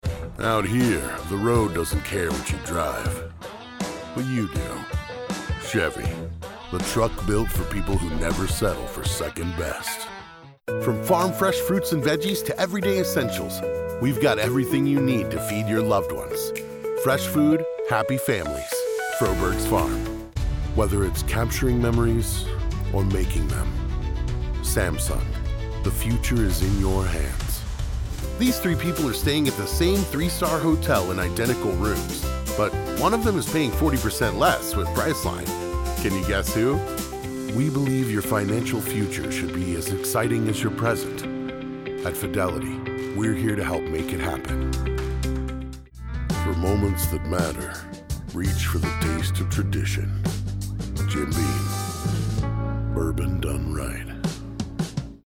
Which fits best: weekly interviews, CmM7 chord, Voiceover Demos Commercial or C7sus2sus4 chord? Voiceover Demos Commercial